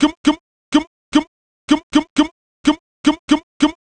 cch_vocal_gum_125.wav